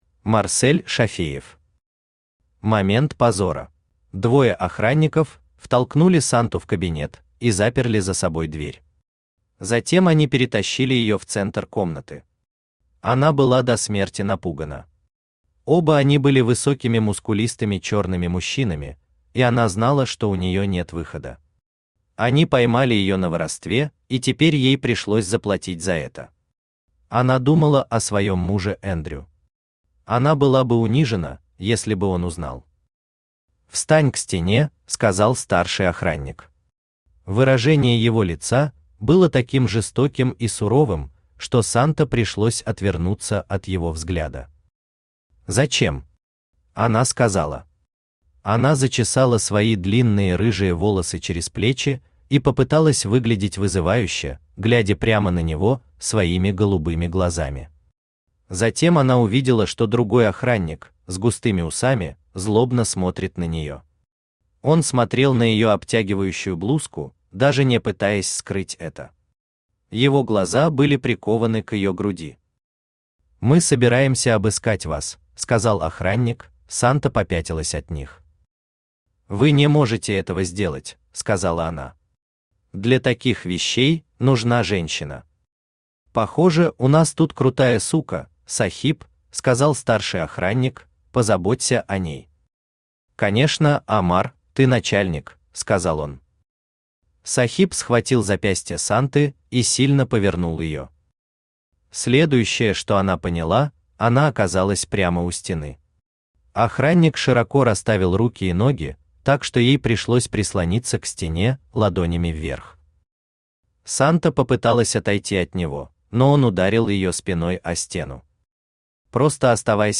Аудиокнига Момент позора | Библиотека аудиокниг
Aудиокнига Момент позора Автор Марсель Зуфарович Шафеев Читает аудиокнигу Авточтец ЛитРес.